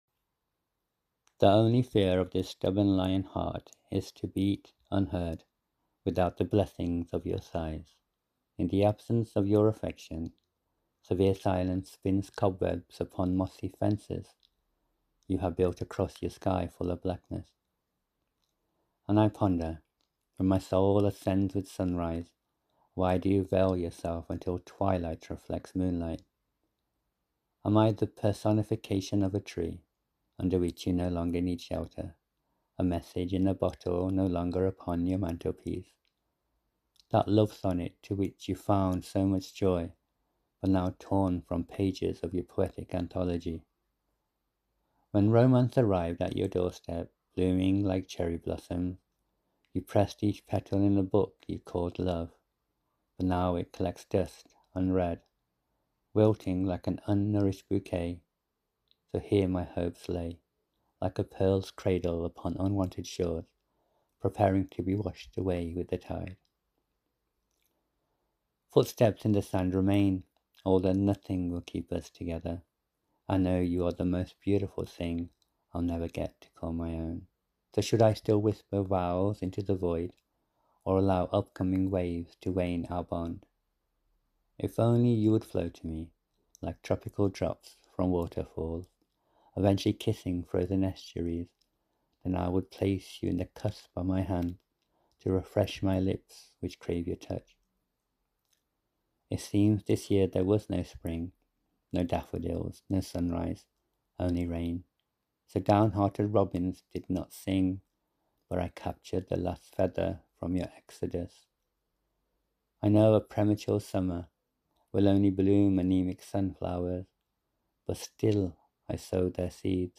Your voice is softer than I'd 'imagined', probably to match poem's mood and it does.